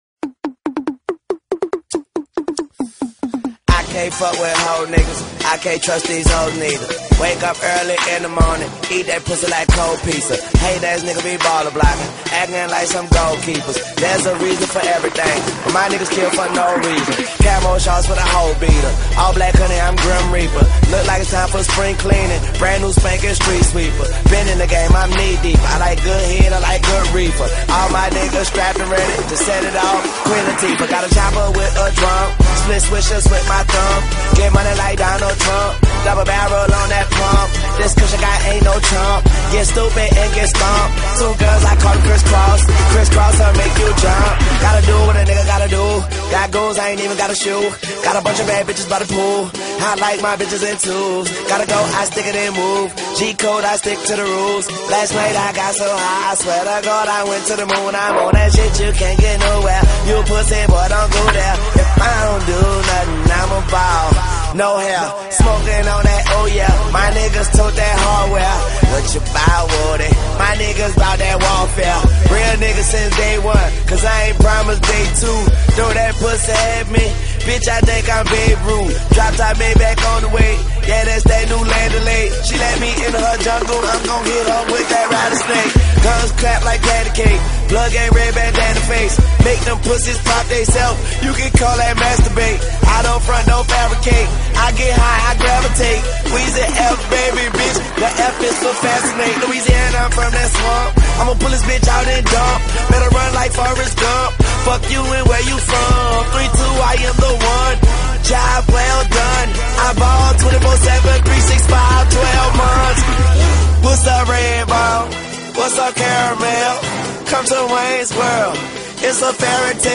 Hip Hop, Mixtape, Music, Rap